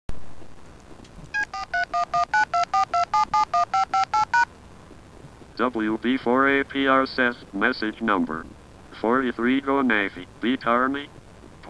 You will notice the voice synthesizer on PSAT-2 is much better than the one on QIKCOM-2.